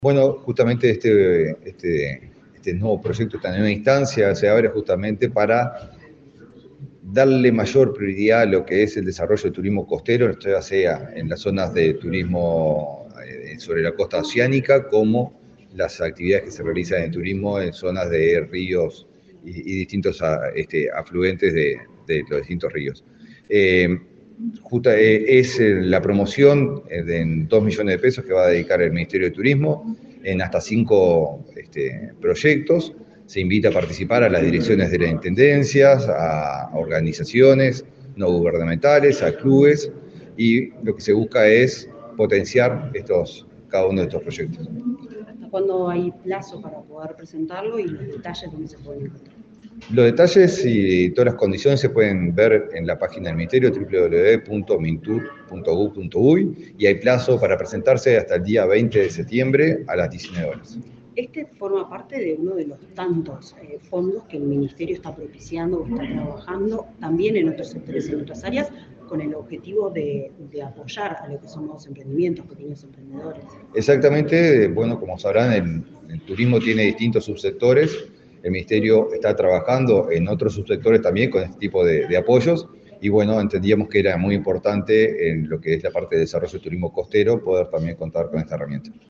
Declaraciones del ministro de Turismo, Eduardo Sanguinetti
Declaraciones del ministro de Turismo, Eduardo Sanguinetti 31/07/2024 Compartir Facebook X Copiar enlace WhatsApp LinkedIn El ministro de Turismo, Eduardo Sanguinetti, participó, este miércoles 31 en Montevideo, en la presentación del llamado titulado Fondo para el Desarrollo Costero Sostenible. Luego, dialogó con la prensa.